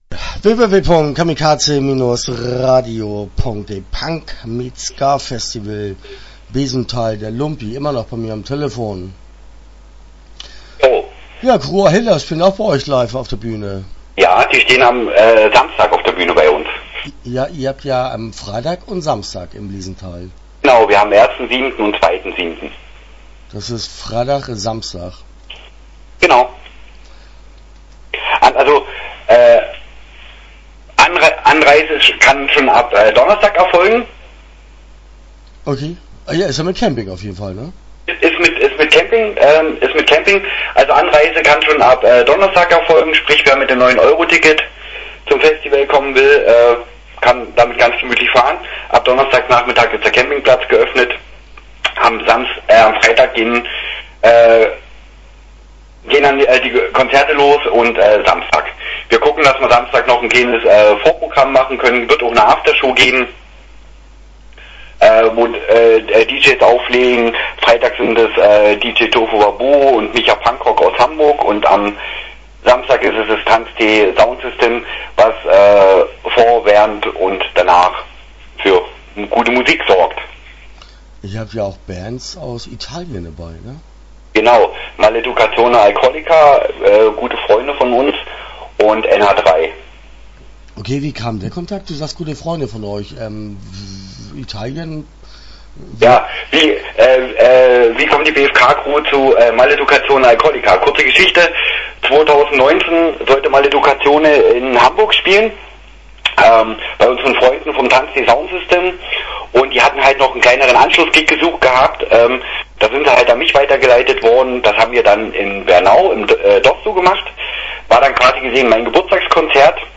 PunkmeetsSka - Interview Teil 1 (9:43)